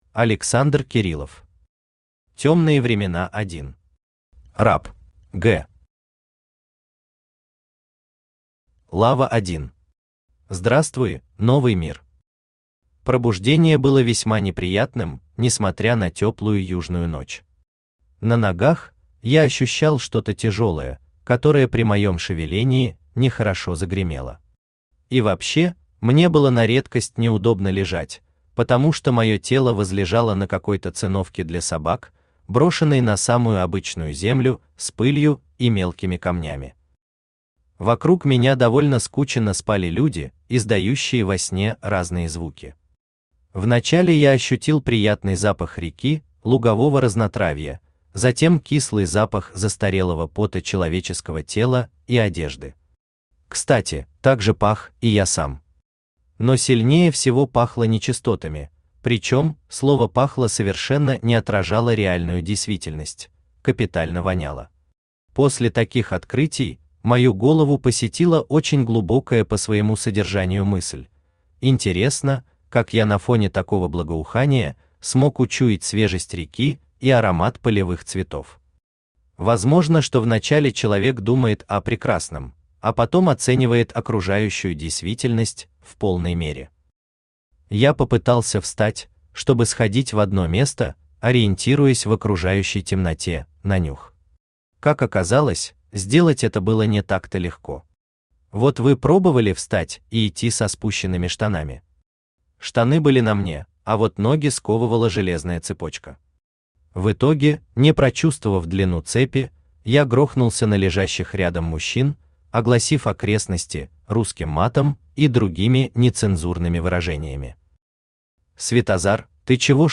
Аудиокнига Тёмные времена 1. Раб | Библиотека аудиокниг
Раб Автор Александр Леонидович Кириллов Читает аудиокнигу Авточтец ЛитРес.